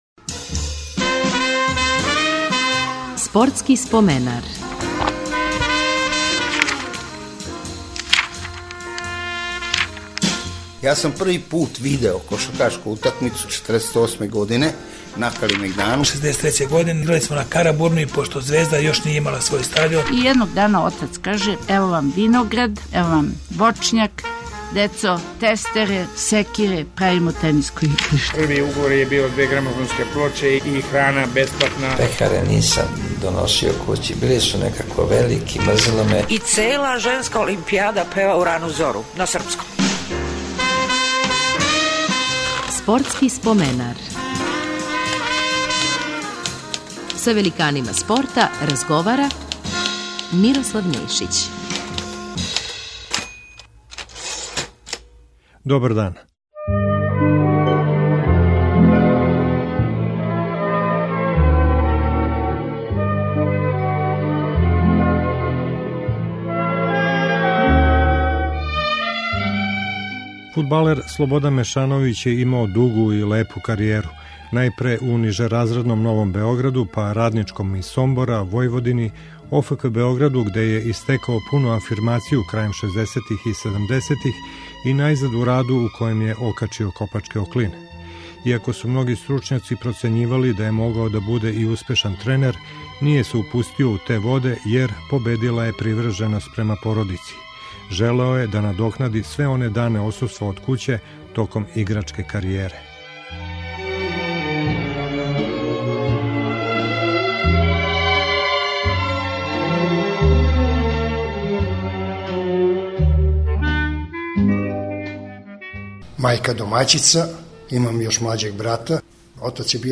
Гост ће бити фудбалер